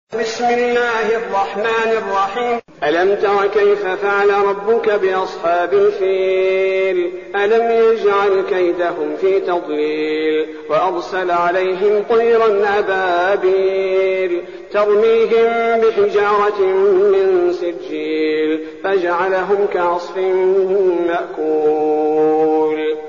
المكان: المسجد النبوي الشيخ: فضيلة الشيخ عبدالباري الثبيتي فضيلة الشيخ عبدالباري الثبيتي الفيل